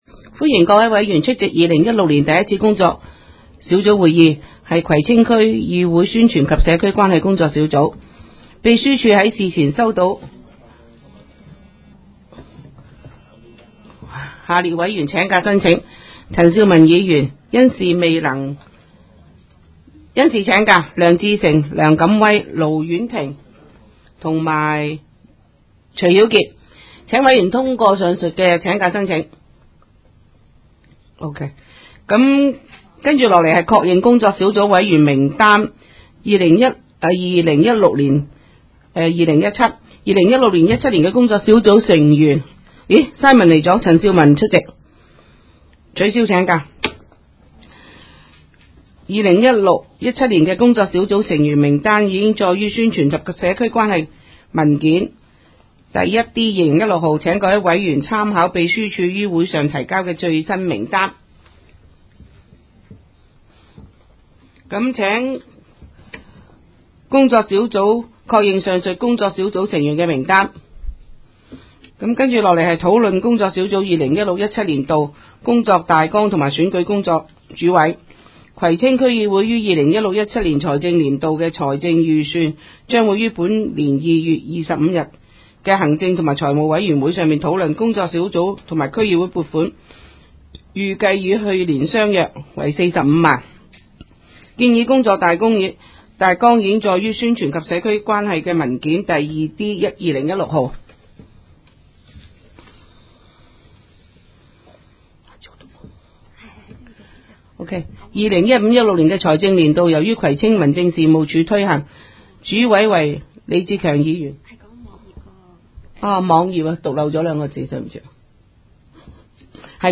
工作小组会议的录音记录